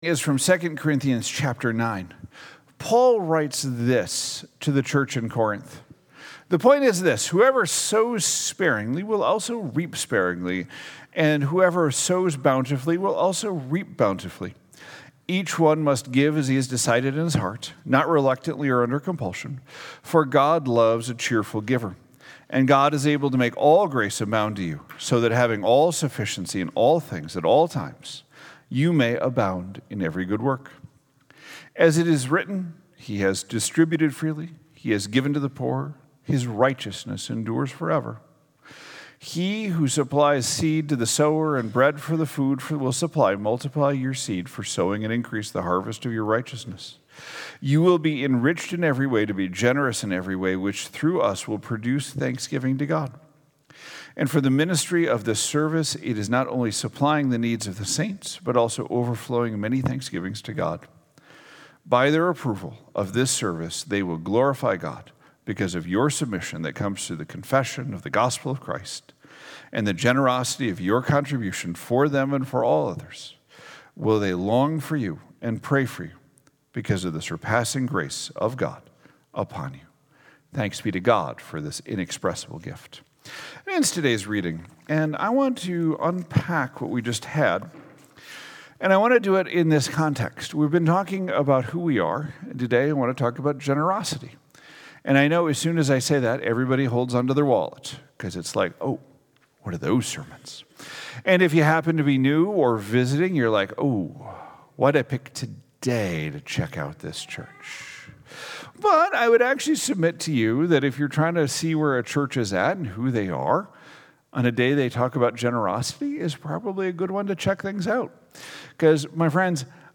2025 Who Are We Generous Spirit Sunday Morning Sermon Series